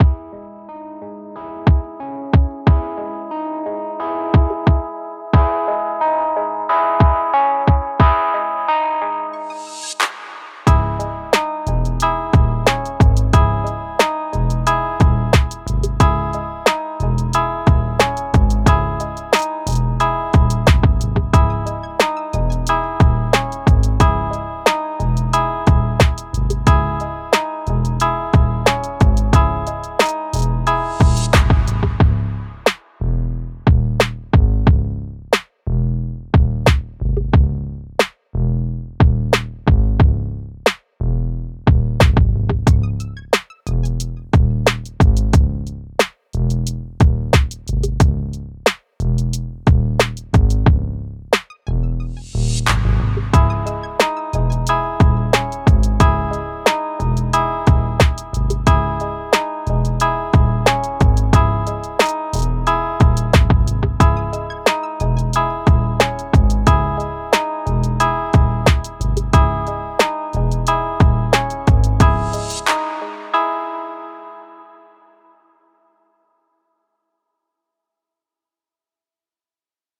Production Music Examples